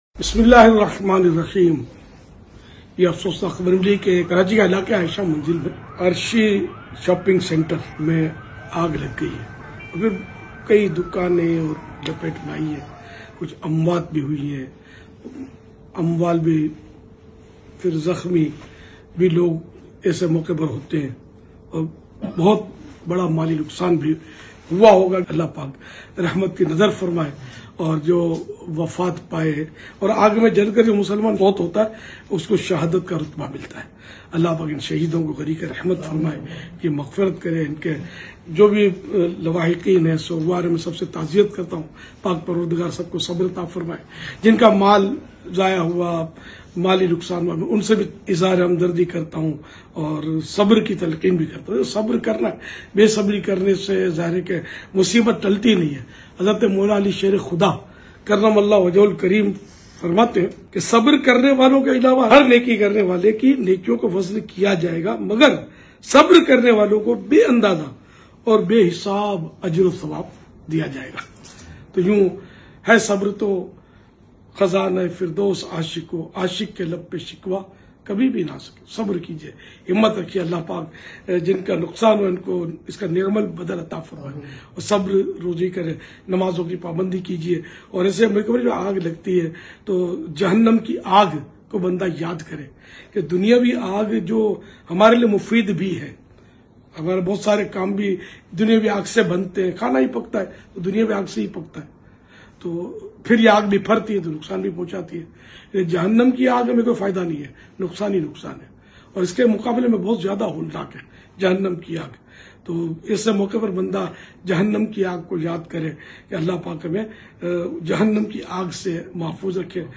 Dua Aur Taziyati Paigham